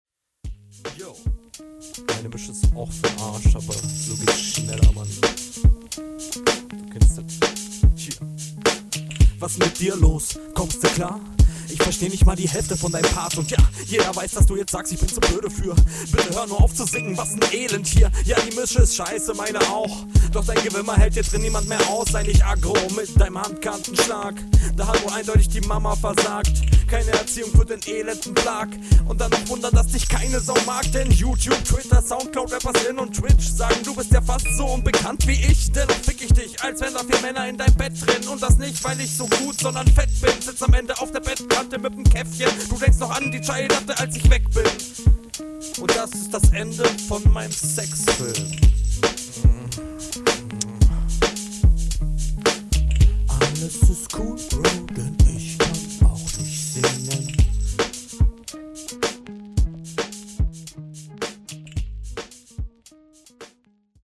Starkes rauschen, beat zu leise.